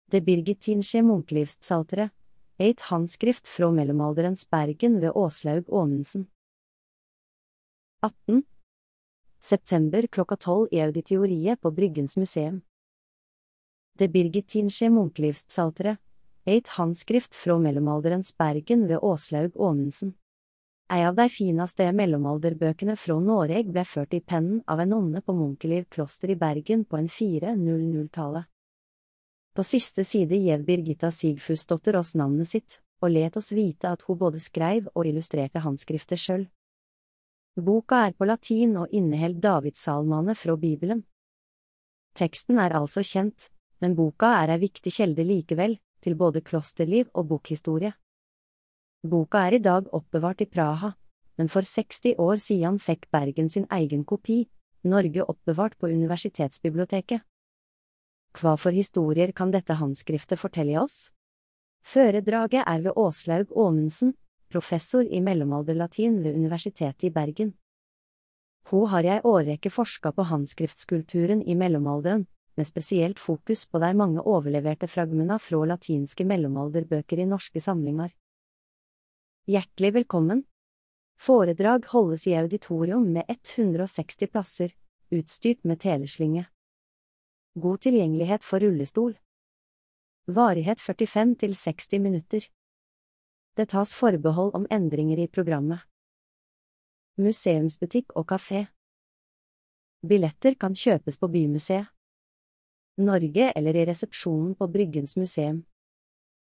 Det birgittinske Munkelivspsalteret: Eit handskrift frå mellomalderens Bergen. Populærvitenskapelige foredrag. Bryggens Museum